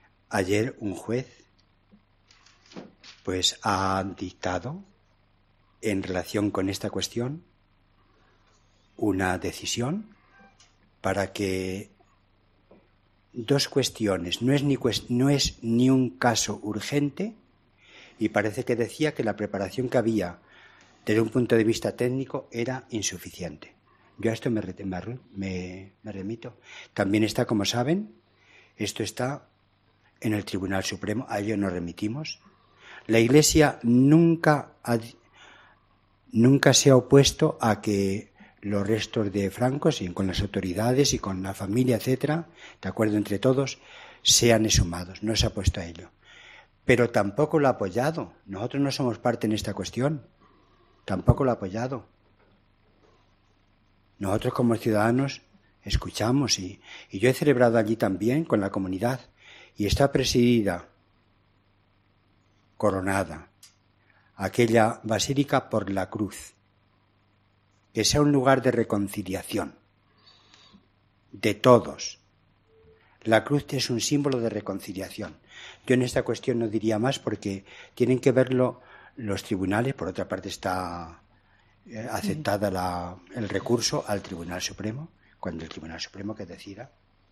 Durante la rueda de prensa de la Conferencia Episcopal española celebrada tras la Comisión Permanente, el cardenal Ricardo Blázquez se ha referido a la exhumación de los restos de Franco.